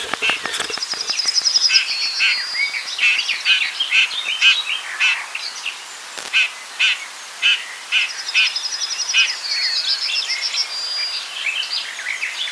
But while trying to record an Icterine Warbler it came again: A Red-Backed Shrike calling! 💚